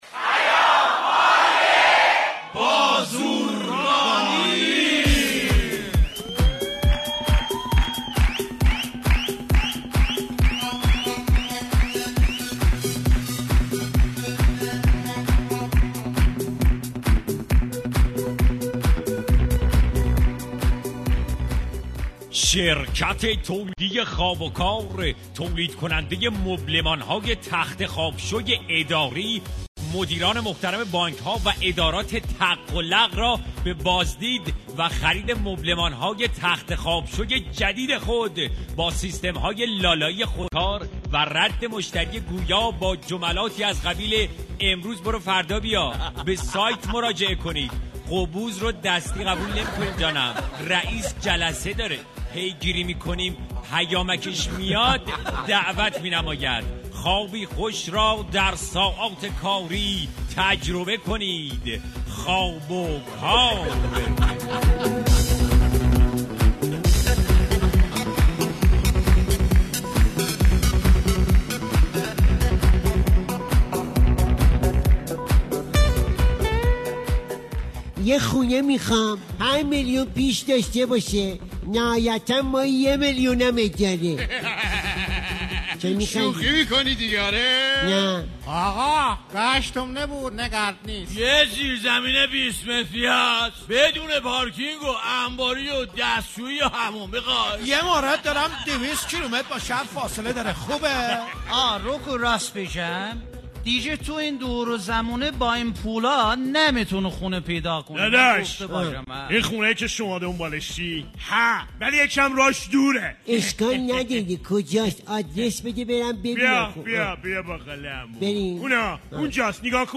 برنامه طنز رادیو ایران